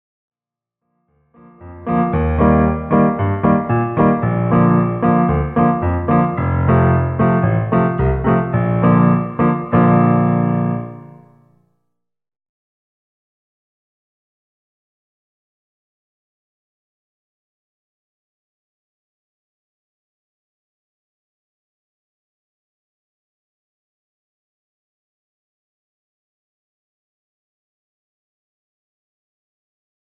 Schule für Viola
Besetzung: Viola